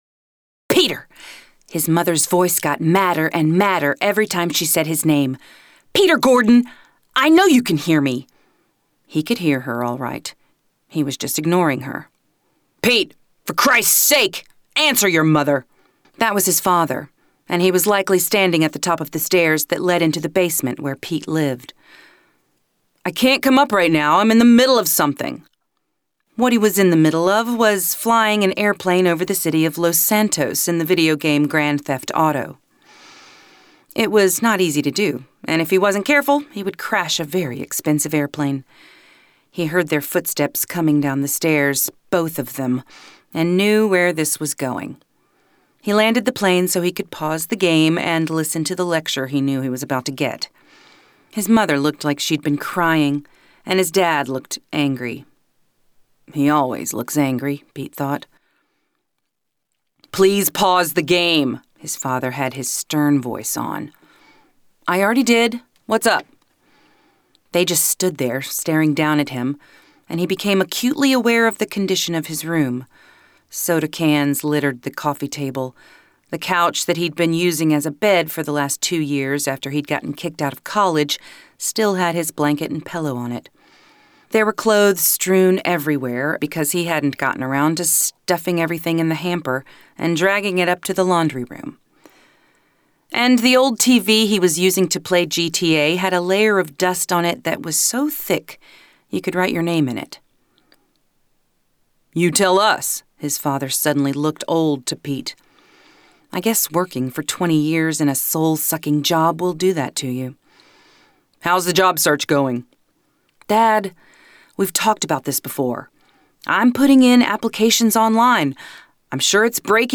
Approachable Mom-next-door with wit, authority and just a touch of naughty!
Audiobook Romance Sample
Middle Aged